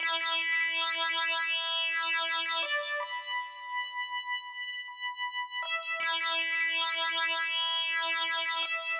模糊的94Bpm
描述：模糊的合成器垫
Tag: 94 bpm Hip Hop Loops Pad Loops 1.72 MB wav Key : Unknown